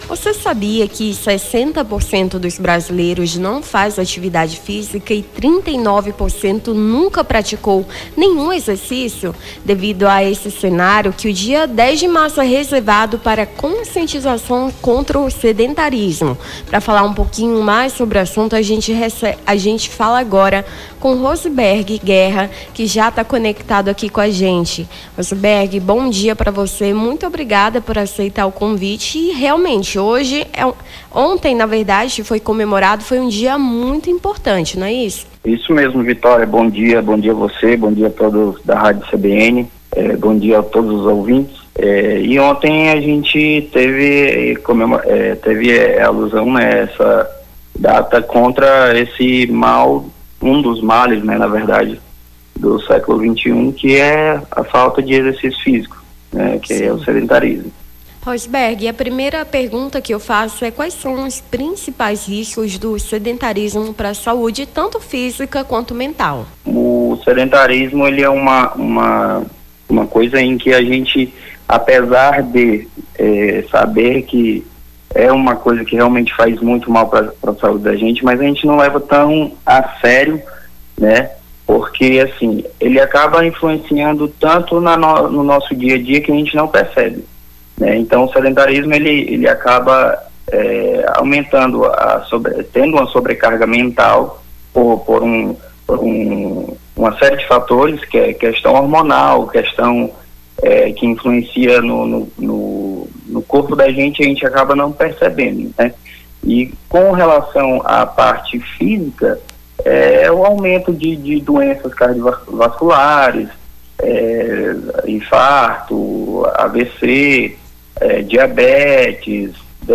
Nome do Artista - CENSURA - ENTREVISTA DIA COMBATE DO SEDENTARISMO (11-03-25).mp3